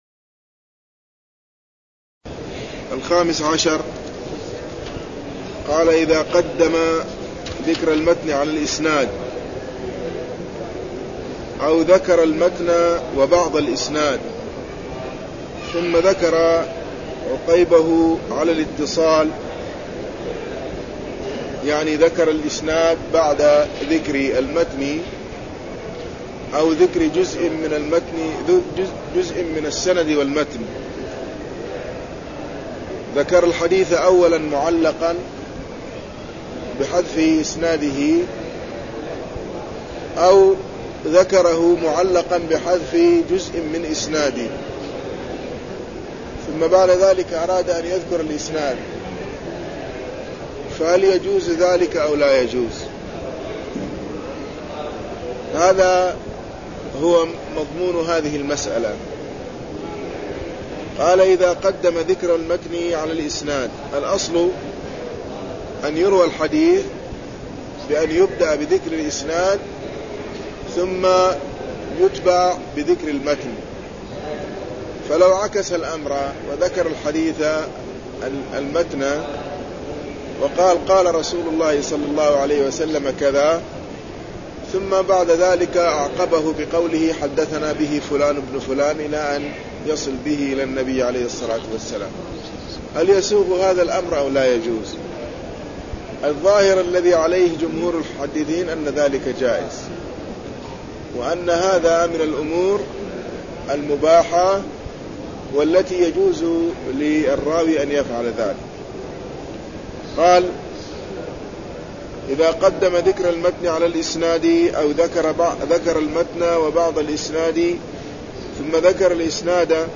درس عن رواية الحديث (028)
المكان: المسجد النبوي